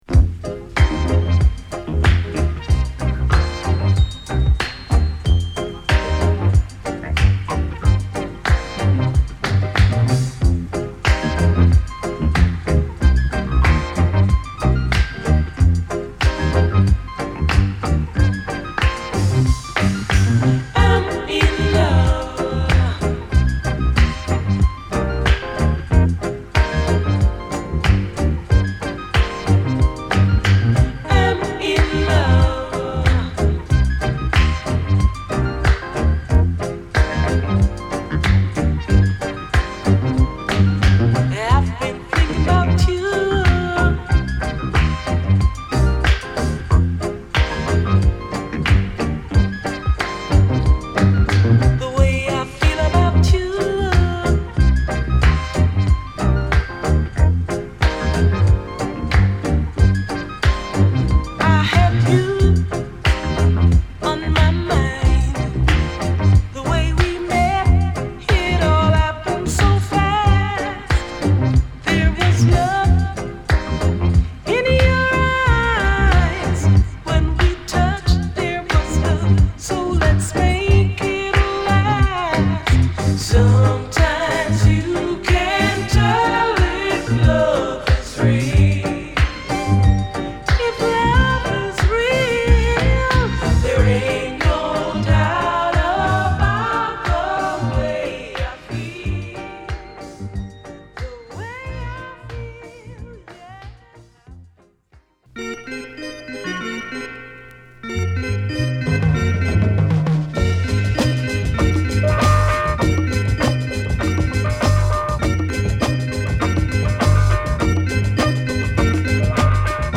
妖しく光るハモンド、ワウ・ギターが炸裂するオブスキュアな70'sラテン・ファンク